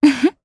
Xerah-Vox-Laugh_jp.wav